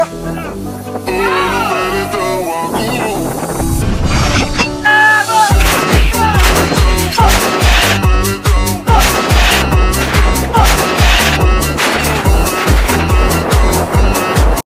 Chinese cannon#artillery#weaponry